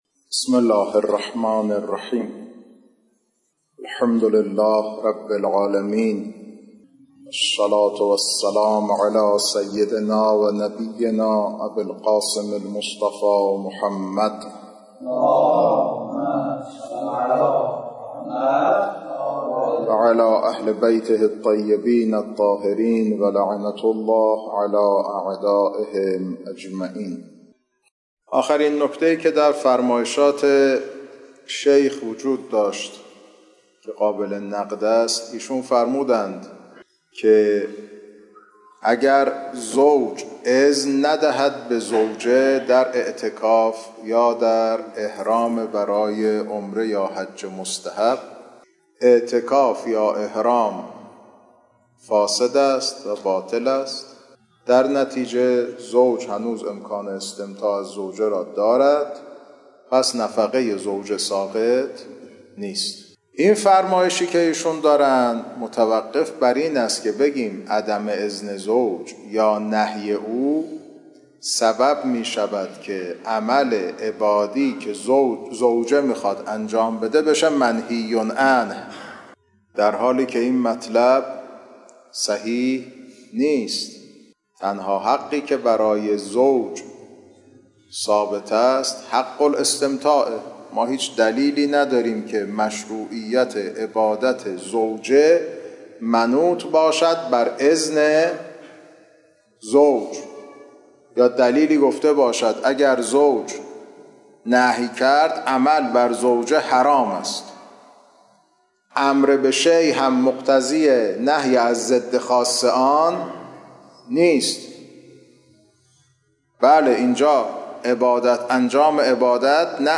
خارج فقه